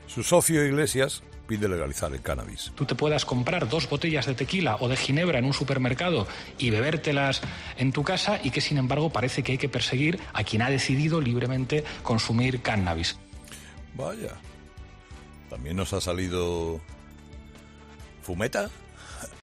La defensa de Iglesias para la legalización del cannabis no ha pasado inadvertida para Carlos Herrera, que tirando de sarcasmo se ha preguntado: "Vaya, ¿también nos ha salido fumeta?"